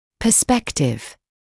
[pə’spektɪv][пэ’спэктив]перспектива; угол зрения; перспективный
perspective.mp3